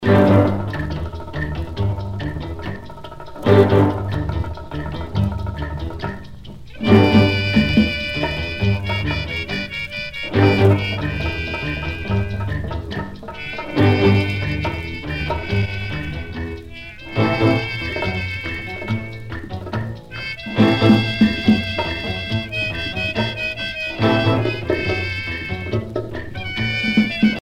danse : rumba
Pièce musicale éditée